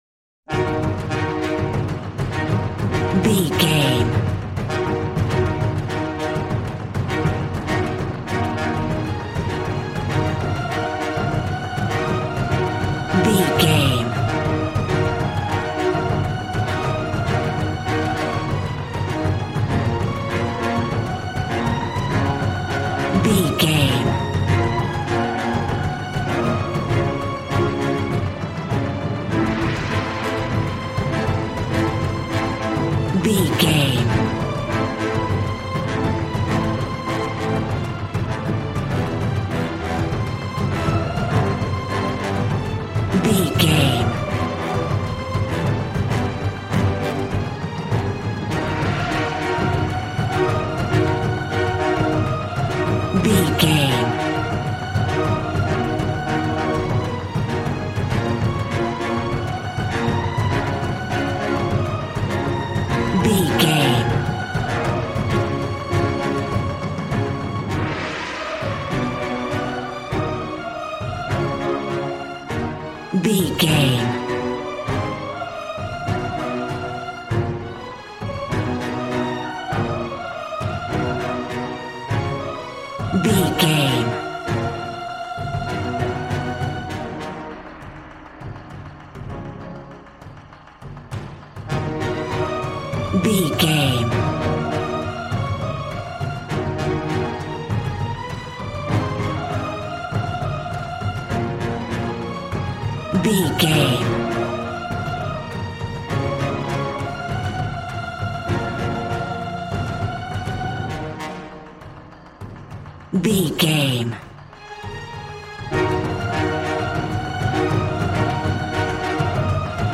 Aeolian/Minor
E♭
regal
cello
double bass